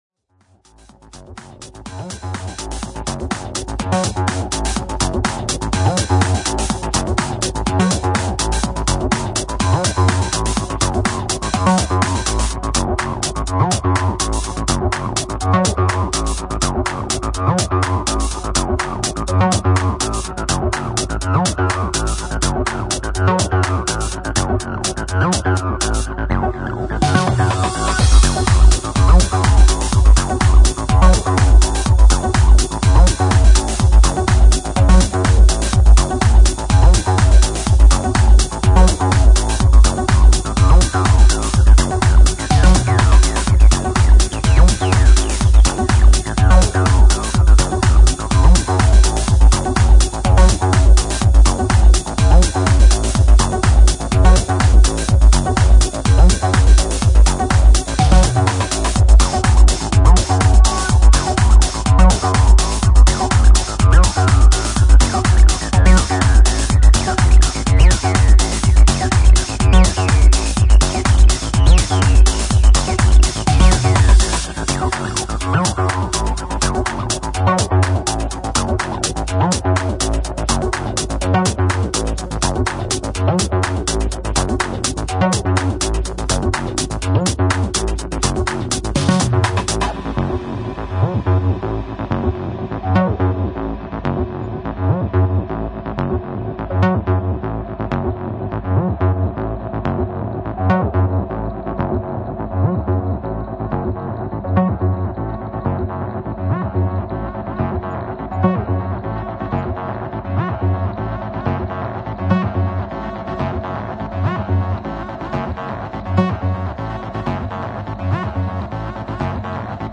久々に直球でBUILD-UPしてきた303がどっちの面もかっこいいです